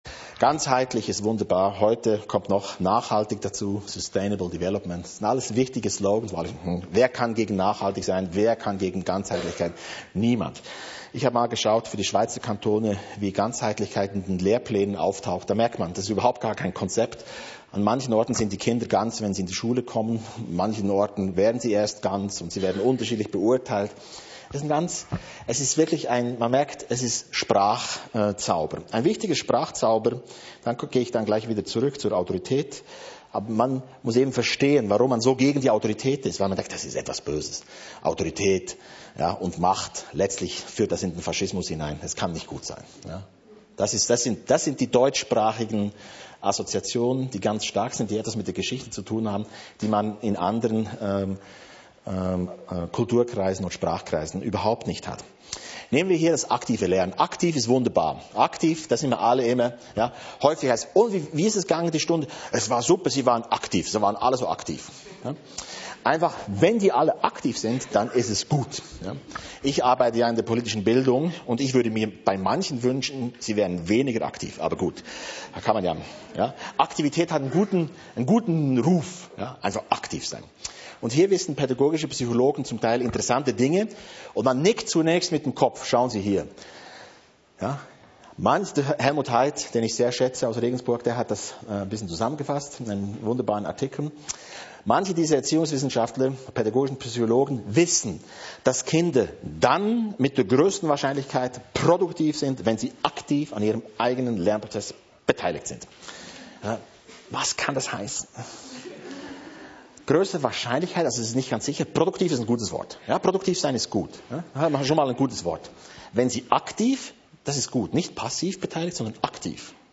Vortrag Autorität, Teil 2